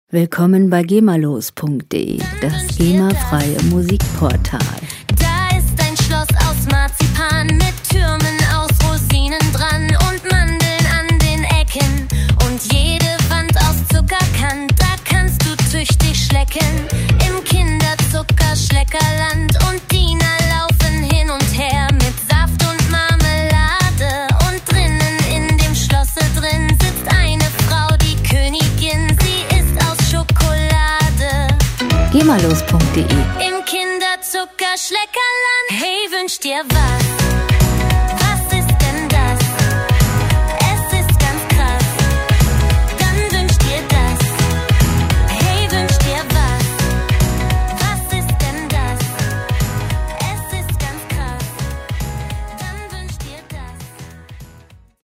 Musikstil: Christmas Pop
Tempo: 100 bpm
Tonart: H-Dur/C-Dur
Charakter: fantasievoll, originell